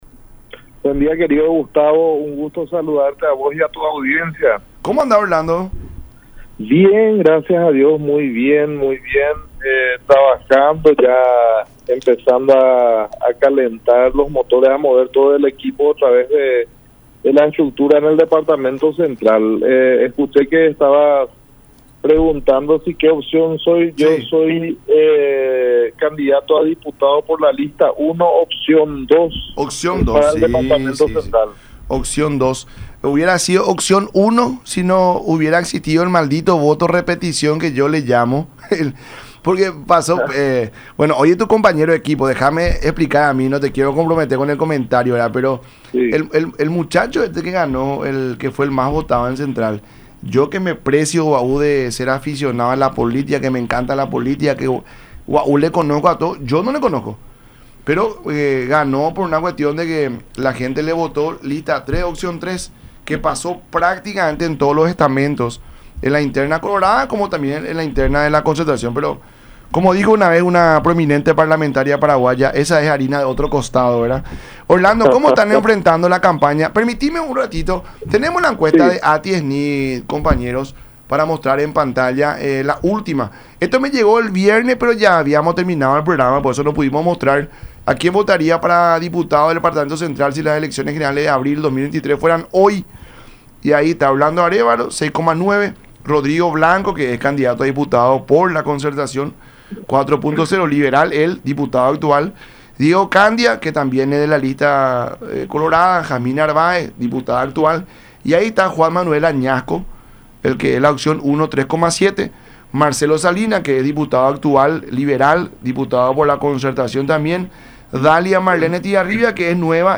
en diálogo con La Mañana De Unión a través de Unión TV y radio La Unión